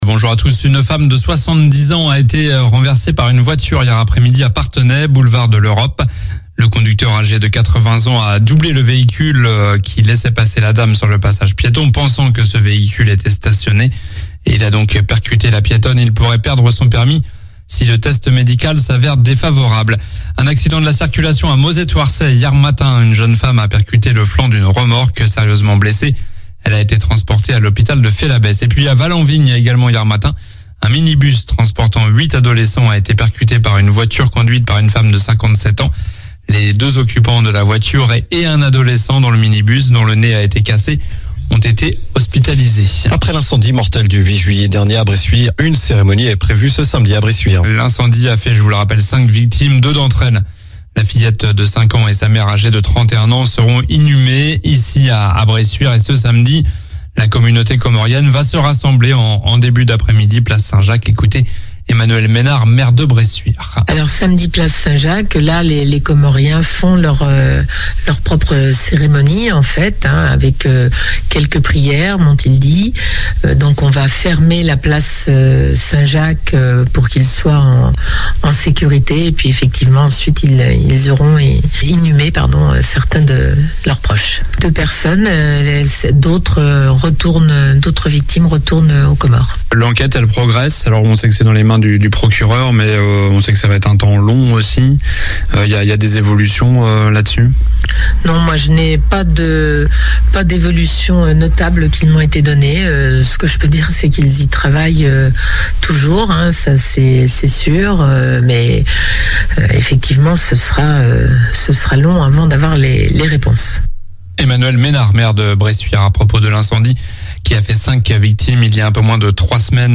Journal du mercredi 27 juillet (matin)